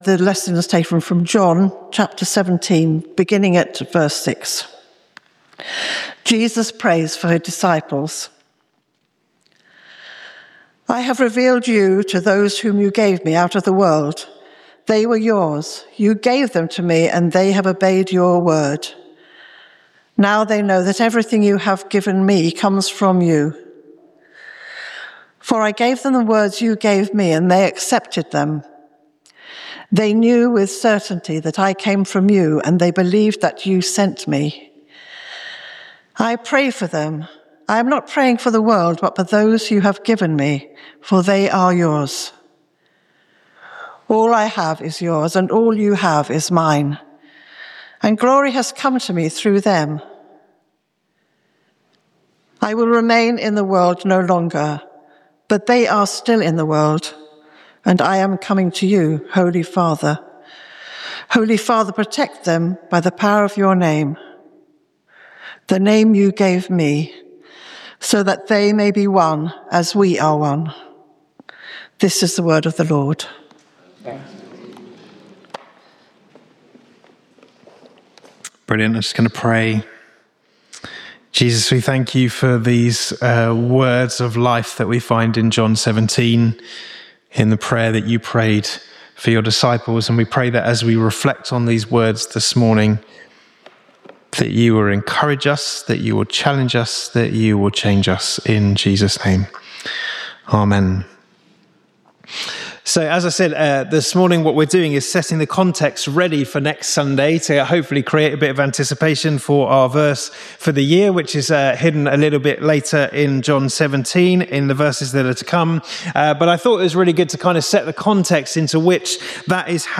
This sermon explores Jesus’ prayer for His disciples spoken in the intimacy of the upper room on the night before His arrest. As Jesus prays not for the world but for those the Father has given Him, we see His desire that His followers would remain one in the face of coming persecution.
Service Type: Communion Location: St Mary’s, Slaugham